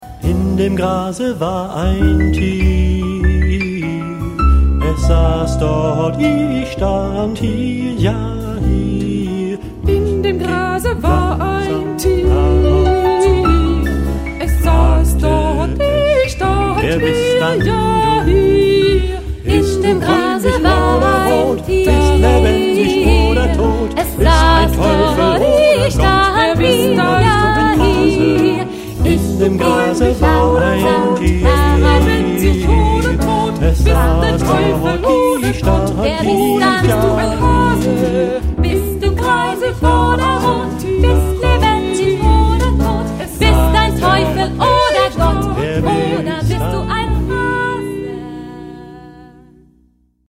... Swing-Kanon ...
Genre-Stil-Form: weltlich ; Kanon ; Vokal Jazz
Charakter des Stückes: erzählend ; lebhaft ; humorvoll
Chorgattung: Ad libitum  (1 gemischter Chor Stimmen )
Tonart(en): e-moll